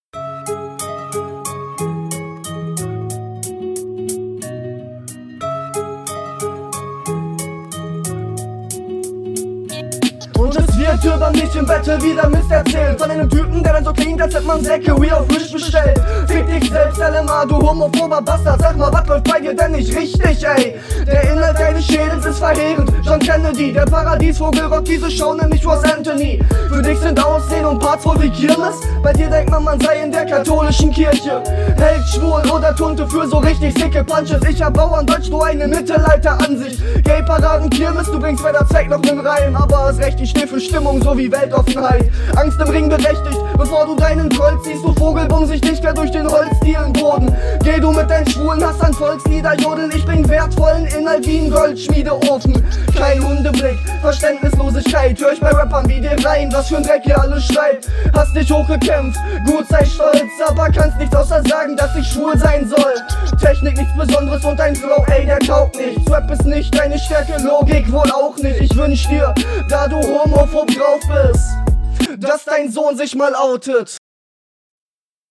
stimme ist zwar lauter aber bei dir ist der mix trotzdem nicht geil. könnt auch …